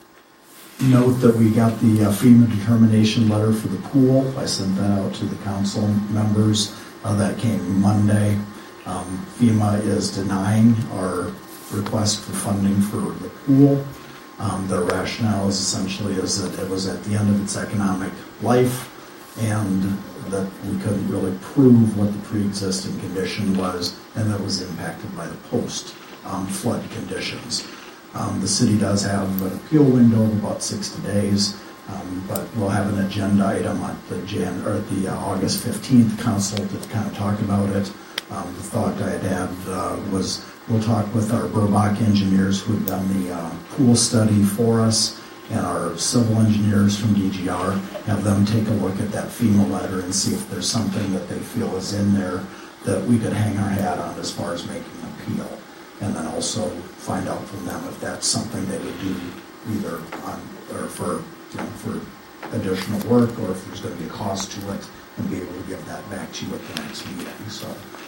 Sound BITE